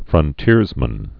(frŭn-tîrzmən)